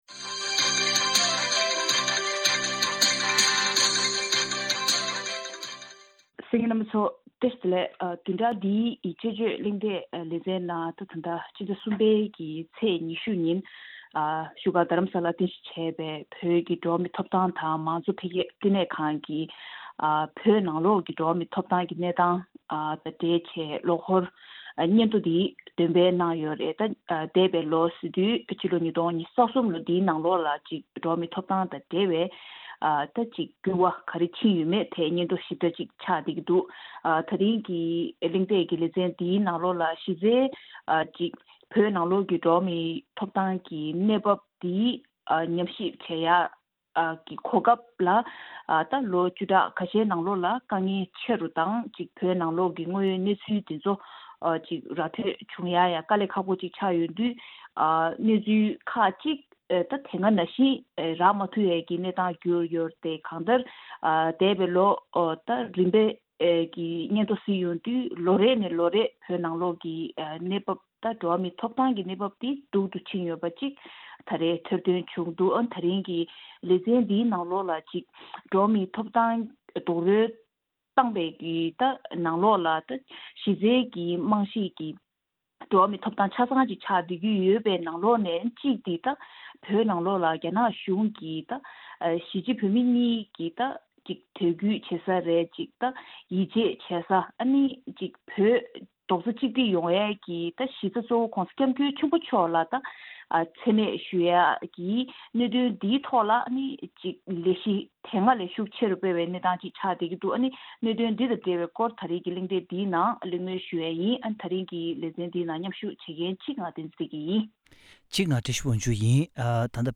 དཔྱད་གཞིའི་བགྲོ་གླེང་ཞུས་པ་ཞིག་གསན་རོགས་གནང་།